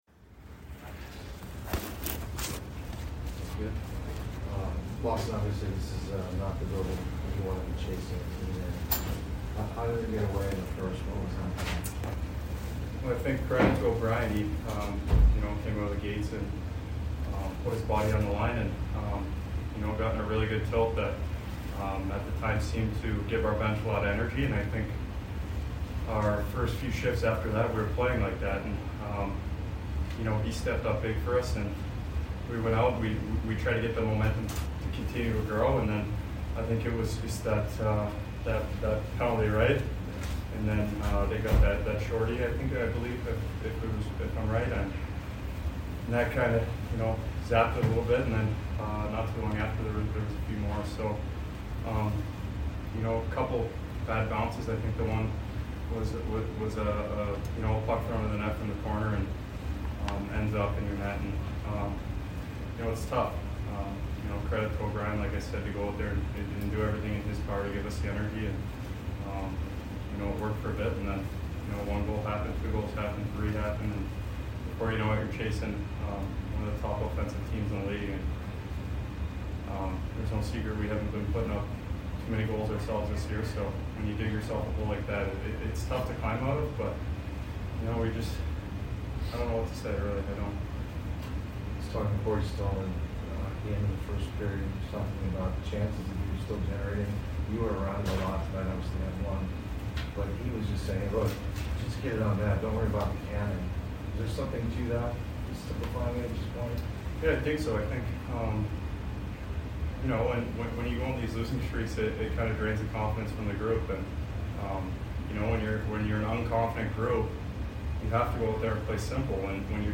Lawson Crouse Post Game Vs ARI 10 - 28 - 21